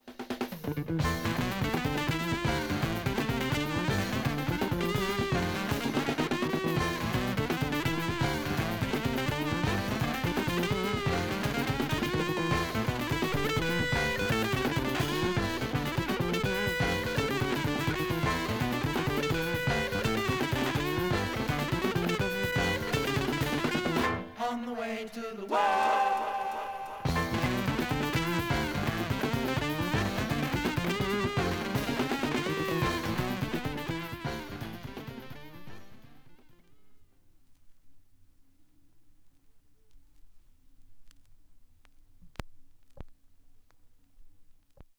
Funk / Soul